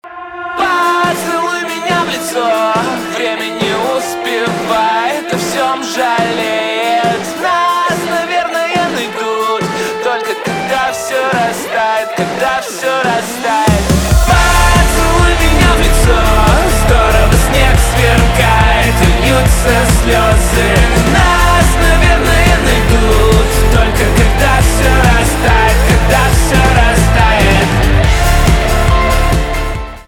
русский рок , гитара , барабаны , кайфовые , нарастающие
качающие , чувственные